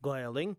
They are essentially the same word in different accents.
Rather than a "w" with the lips, try to make a gentle "u" in the throat.